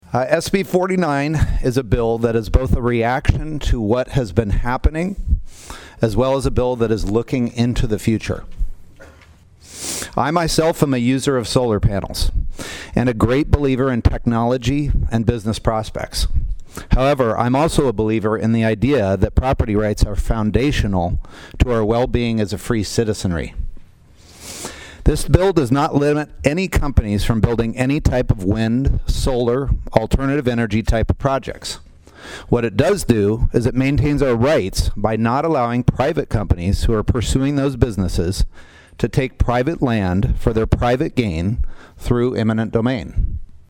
PIERRE, S.D.(HubCityRadio)- The South Dakota Senate State Affairs Committee heard testimony on SB49.
Prime sponsor of the bill was Senator John Carley of Piedmont.